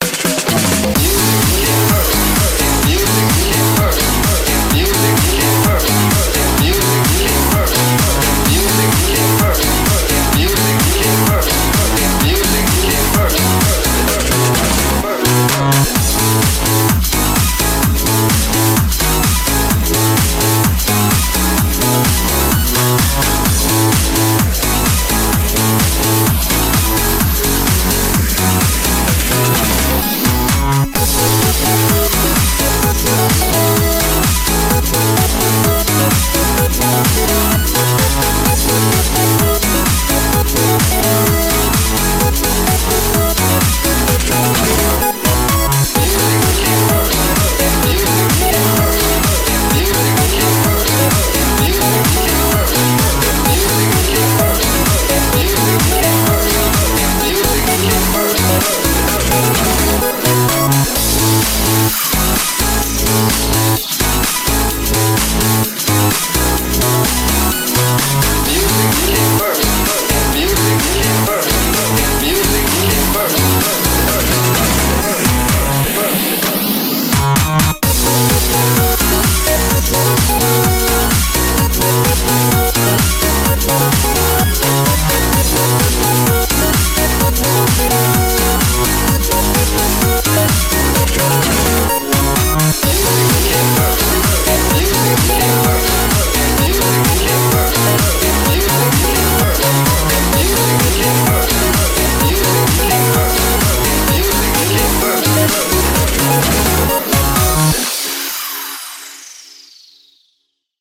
BPM128
Audio QualityMusic Cut